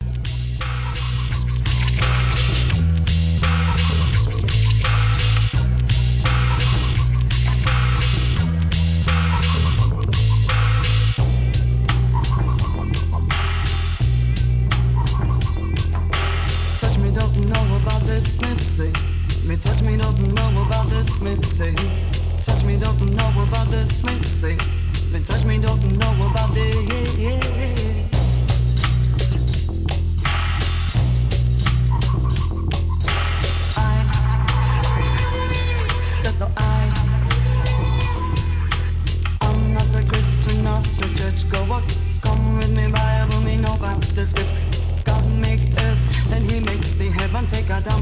HIP HOP/R&B
DOWNTEMPOなブレイクビーツにエスニックなフィメールVOCAL!